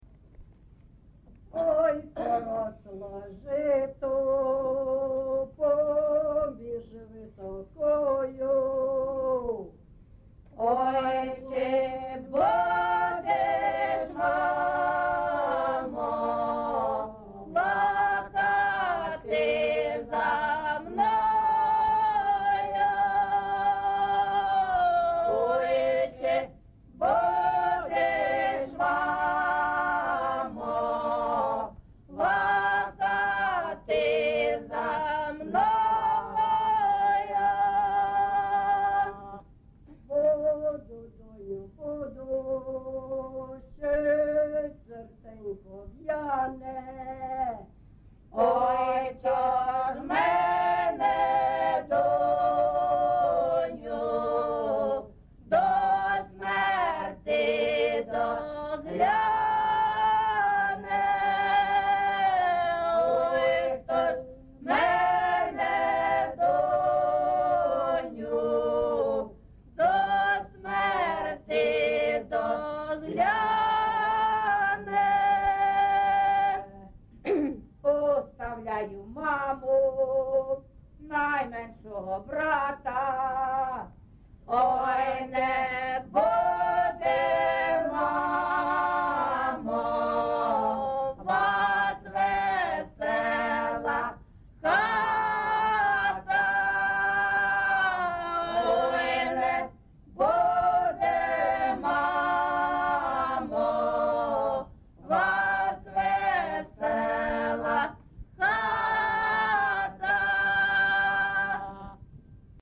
ЖанрПісні з особистого та родинного життя, Жалібні
Місце записус. Іскра (Андріївка-Клевцове), Великоновосілківський (Волноваський) район, Донецька обл., Україна, Слобожанщина